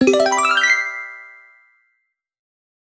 Base game sfx done
Nice Reward 2.wav